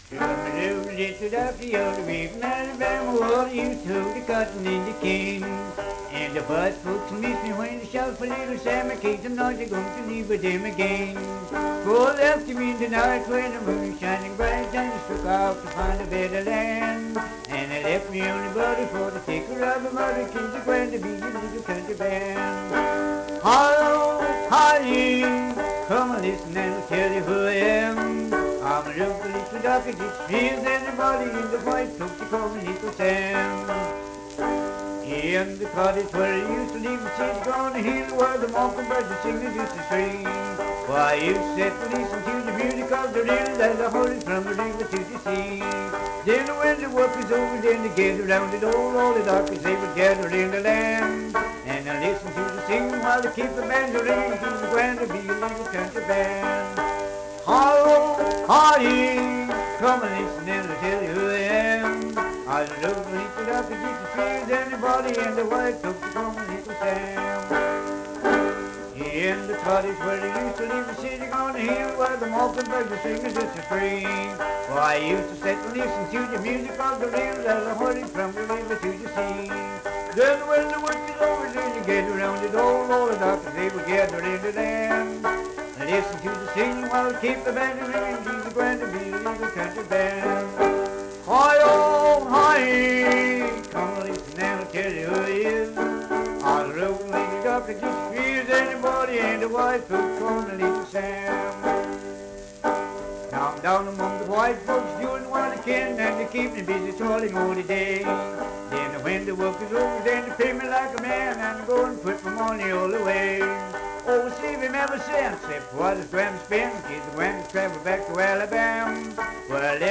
Banjo accompanied vocal music performance
Verse-refrain 3(8) & R(4).
Minstrel, Blackface, and African-American Songs
Voice (sung), Banjo